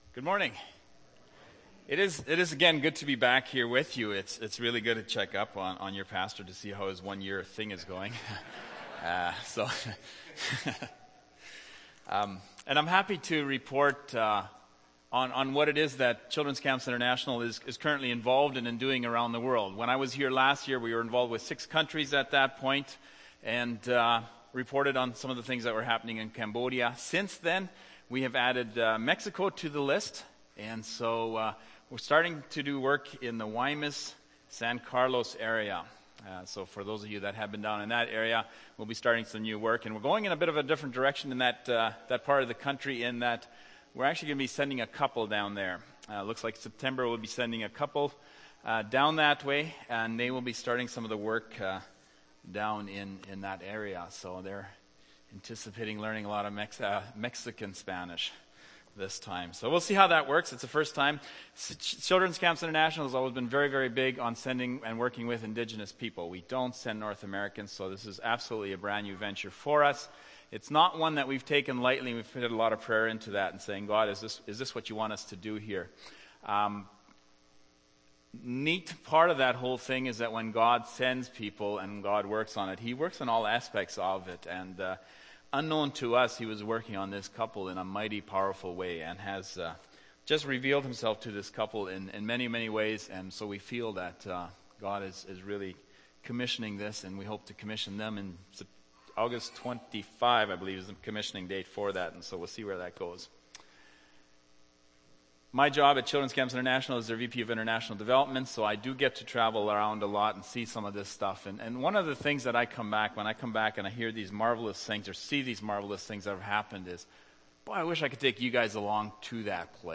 June 30, 2013 – Sermon